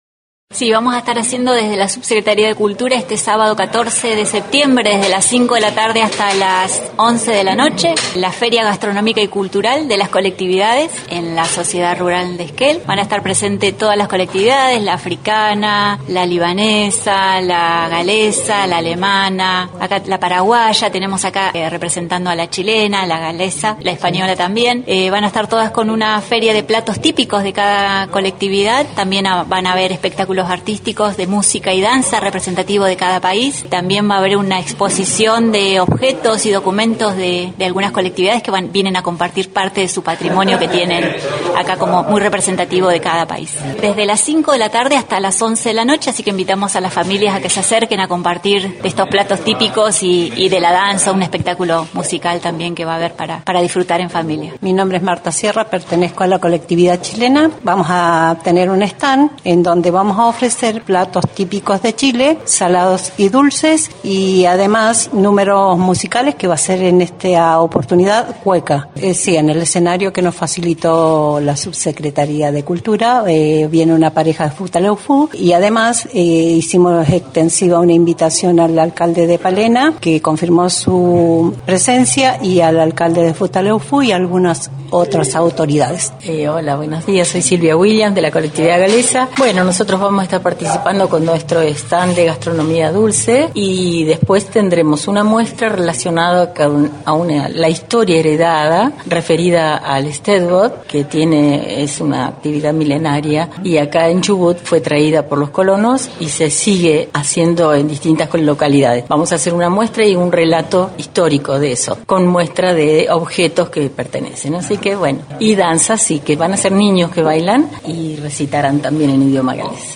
En conferencia de prensa la Directora de Cultura